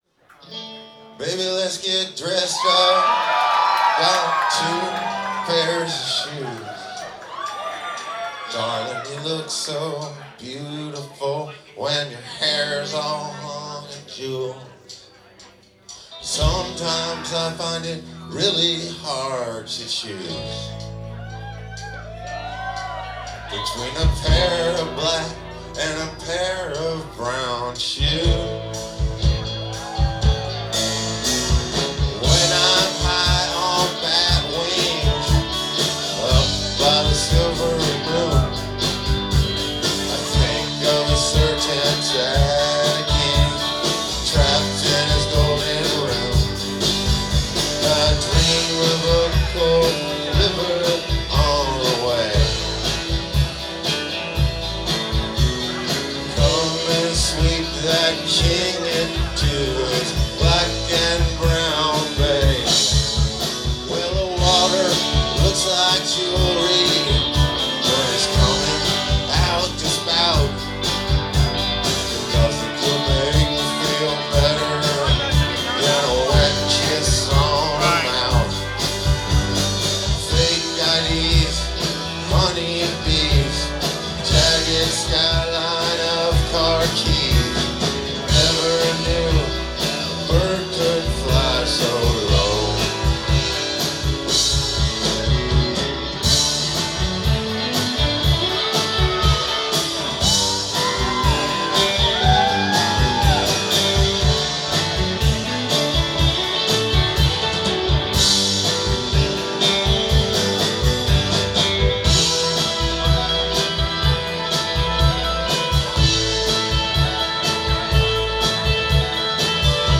Live at The Middle East Downstairs